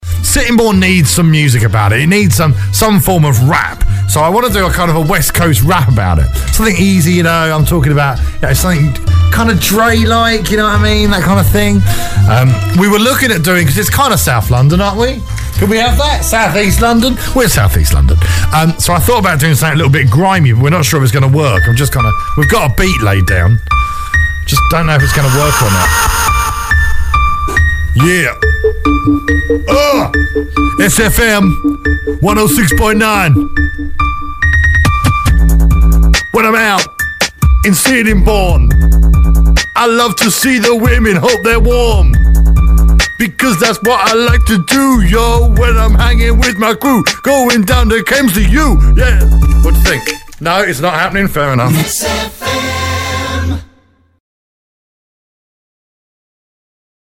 - I tried to make an urban street sounding rap for Sittingbourne